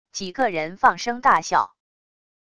几个人放声大笑wav音频